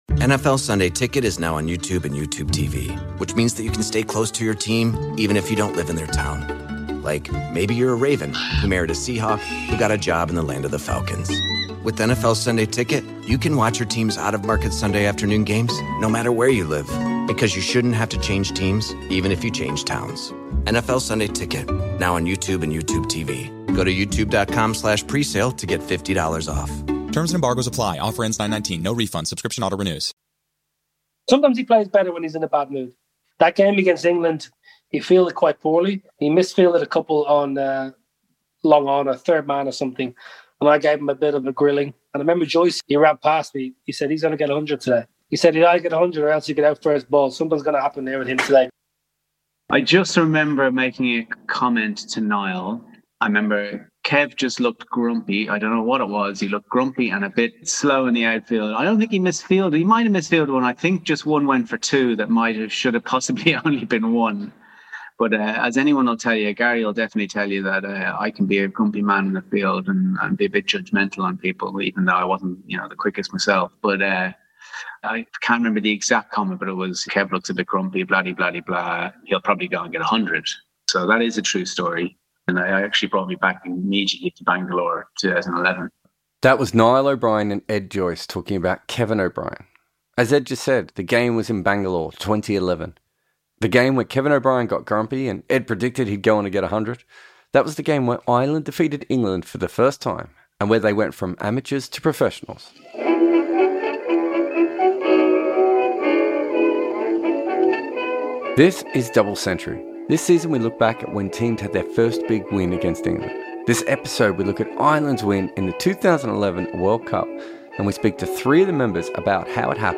In this episode we focus on that incredible chase, but instead of me narrating it, we’ll let the Irish batters take you through it.